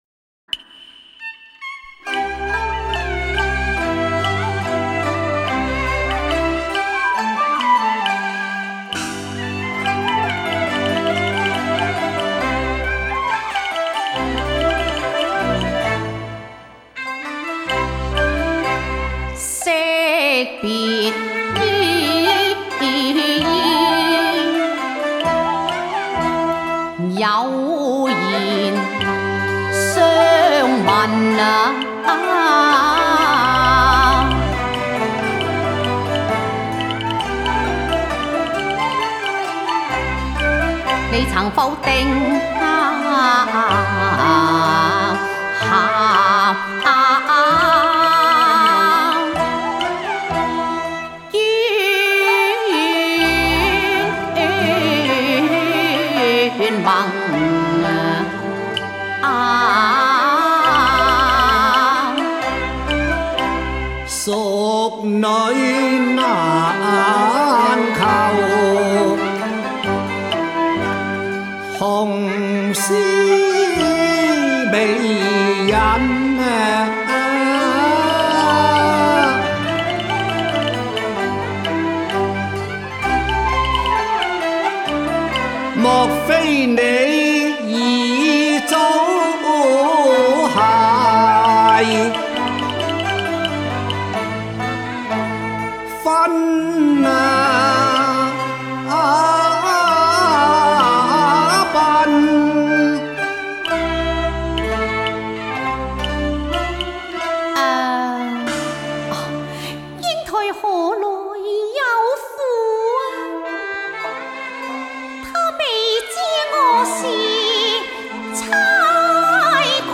粤剧